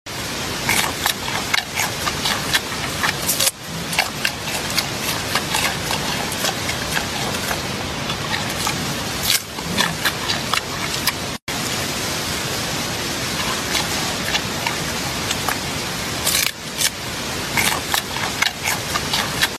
Panda upclose# original sound sound effects free download